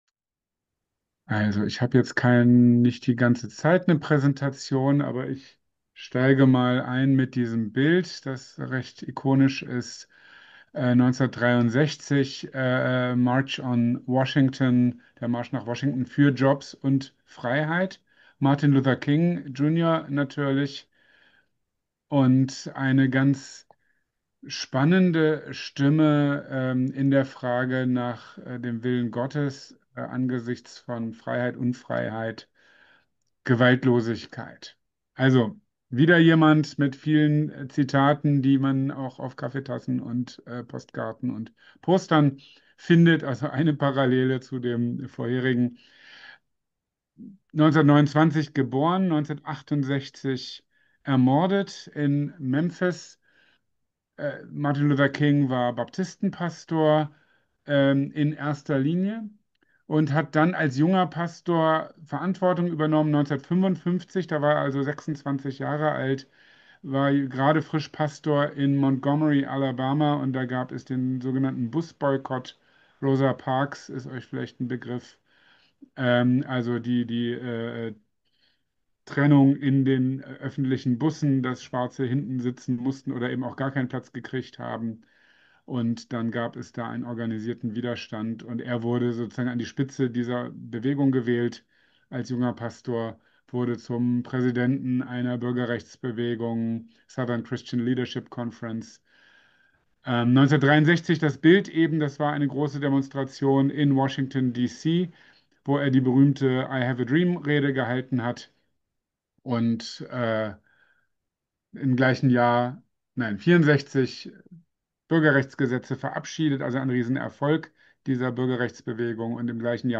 Inmitten einer angespannten Weltlage ist es wichtig auszuloten, was die Bibel zu „Krieg und Frieden“ sagt und wie wir unser Verhalten daran ausrichten können. Ausgehend von der Bergpredigt bieten wir dazu vier AKD-Themenabende per Zoom an. 2....